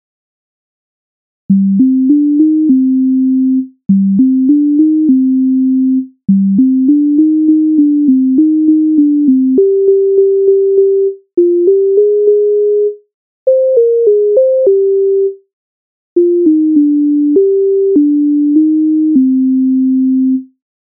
Італійська народна пісня